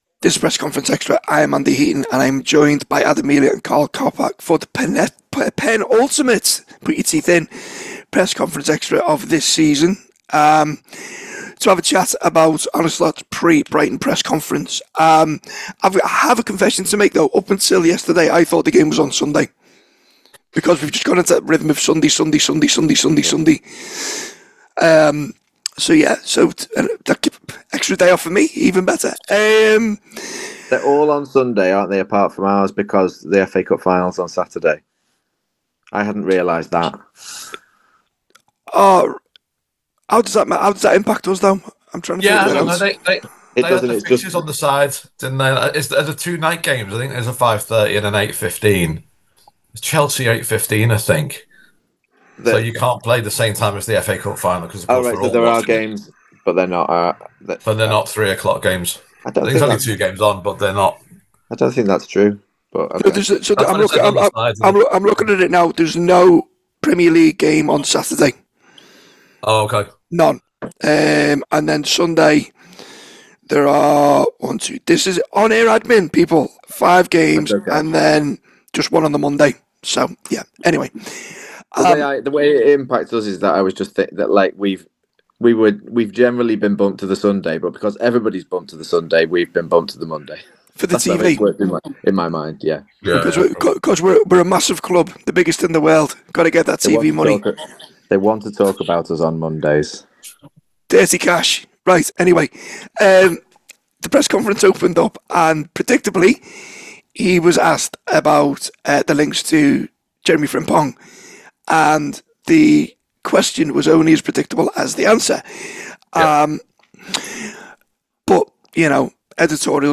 Below is a clip from the show – subscribe for more on the Brighton v Liverpool press conference…